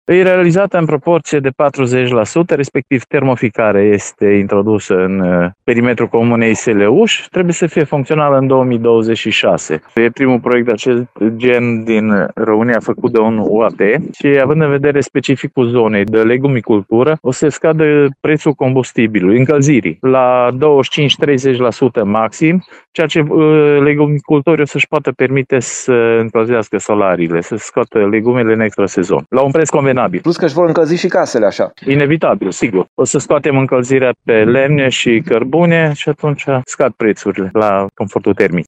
Primarul comunei, Cristian Branc, spune că reţeaua de conducte, în lungime totală de 70 de kilometri, a fost realizată şi acum se lucrează la silozurile din beton, pentru ca ulterior să fie instalată centrala.